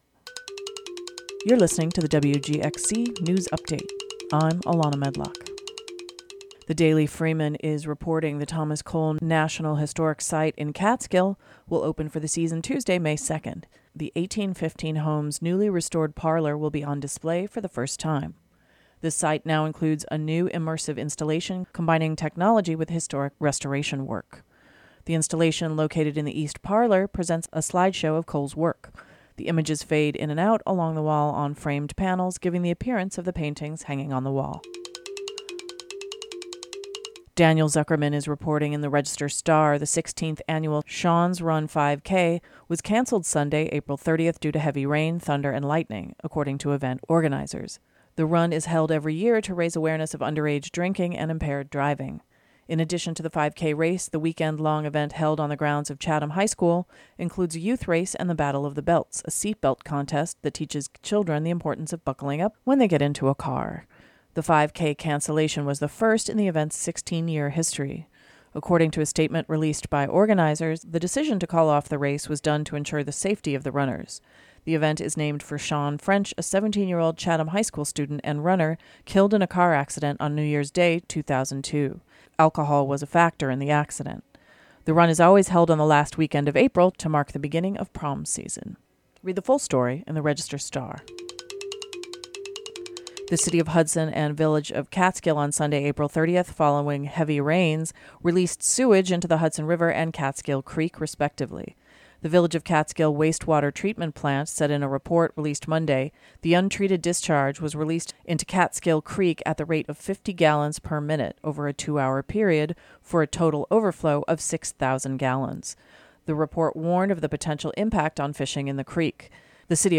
Click here to listen to WGXC's Congressional report, a look back at the week in news for Rep. John Faso (R-Kinderhook).